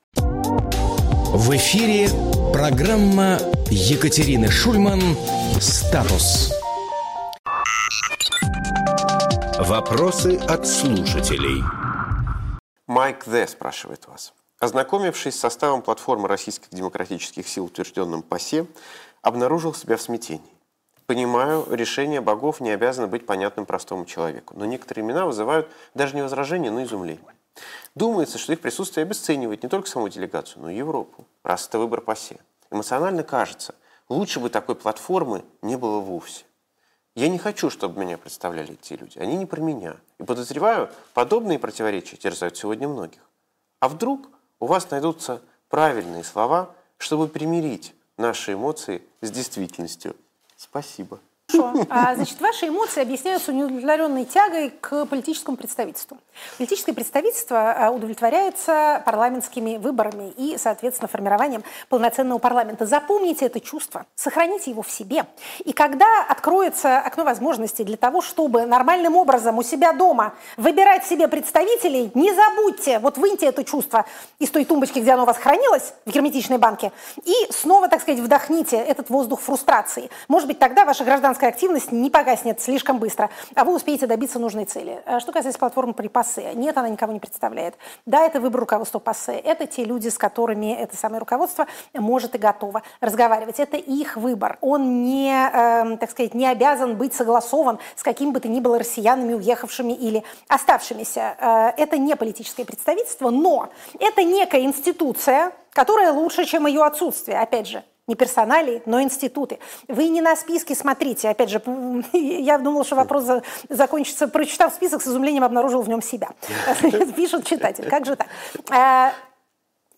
Екатерина Шульманполитолог
Фрагмент эфира от 27.01.2026